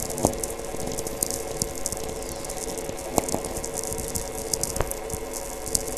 Todmorden, UK